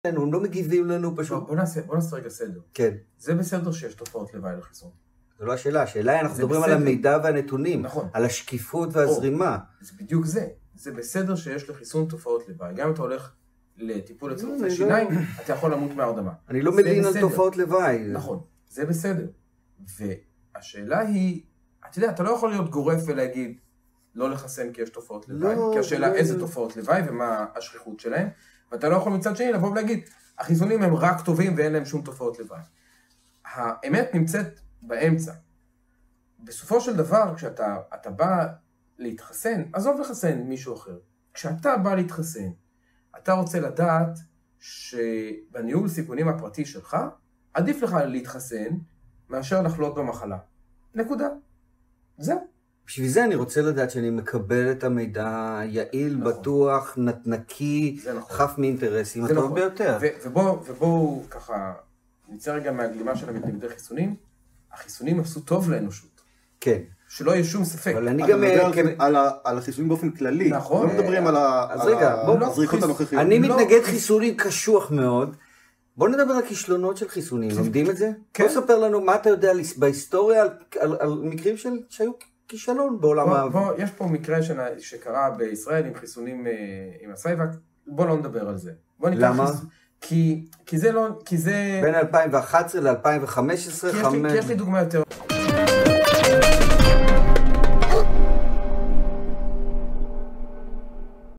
חיסונים עשו טוב לאנושות ?מתוך הריאיון המלא